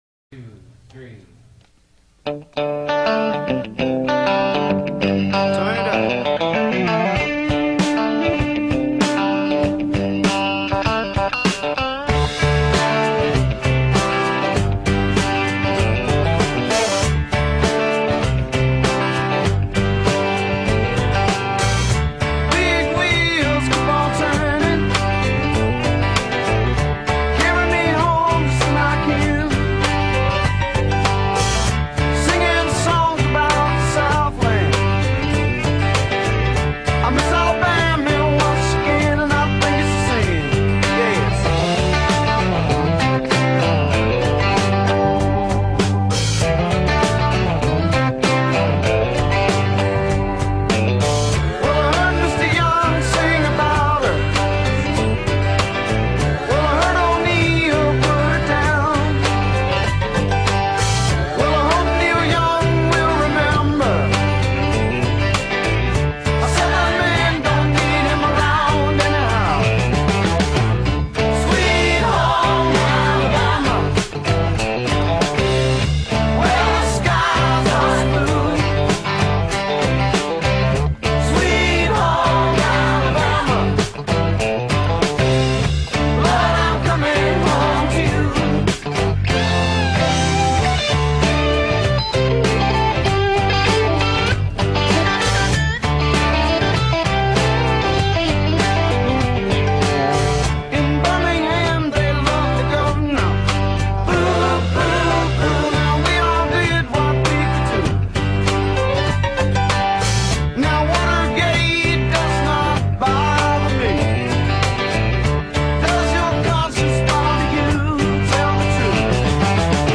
BPM84-104
Audio QualityCut From Video